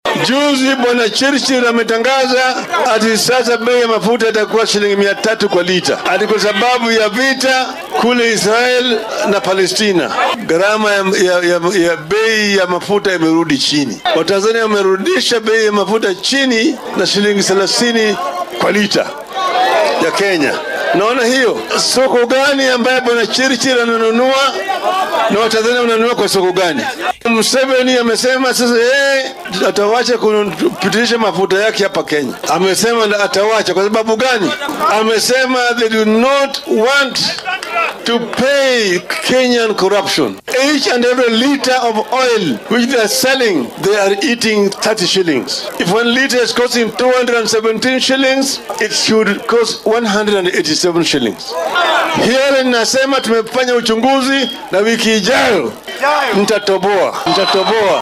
Hoggaamiyaha isbeheysiga mucaaradka ee Azimio la Umoja-One Kenya Raila Odinga ayaa si kulul uga hadlay heshiiska shidaalka ee ay dowladda dhexe la gashay waddamo shisheeye. Mr. Odinga ayaa xusay in arrintan ay horseedday qiimaha sarreeya ee shidaalka.